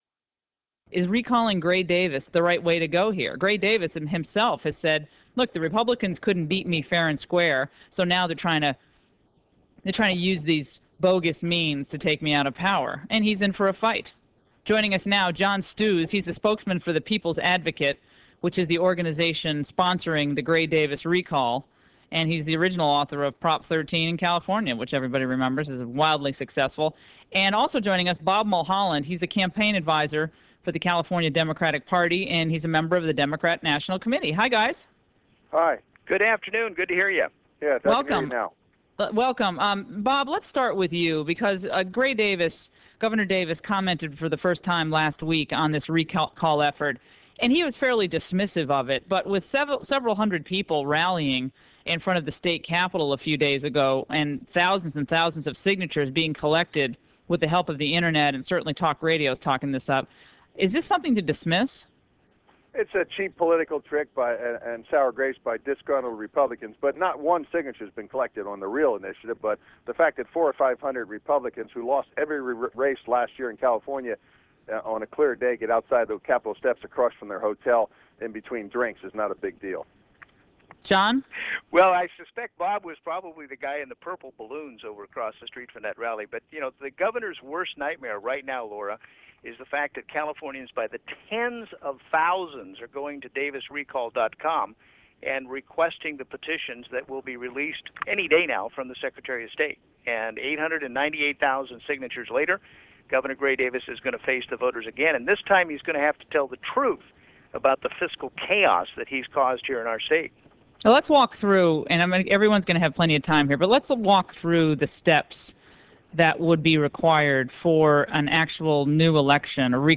Davis Recall Debate on the Laura Ingraham Show